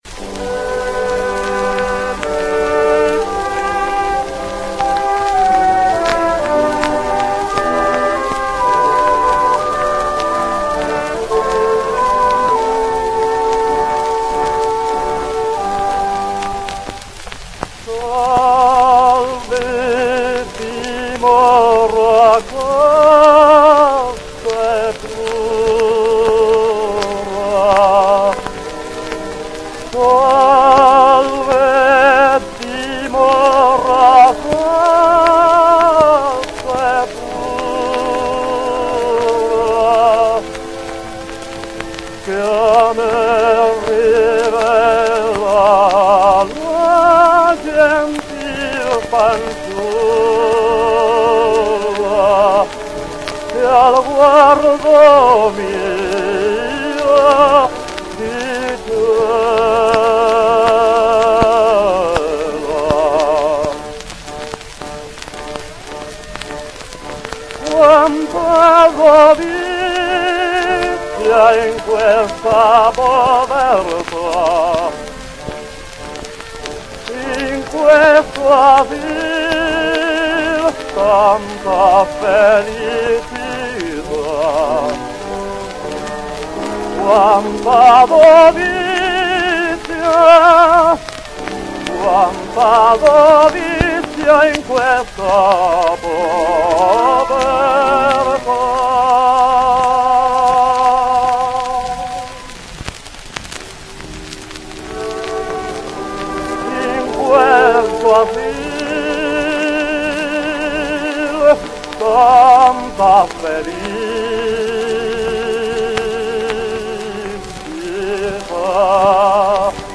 One of the mysteries of this obscure tenor is why he would record selections (like "Salve, dimora", "Spirto gentil" or "Che gelida manina") that require a good top when he had none at all; but he is equally awful beyond the acuti, with his clumsy phrasing, with his constant (and mostly fruitless) search of vocal support, and with his getting lost in the scores.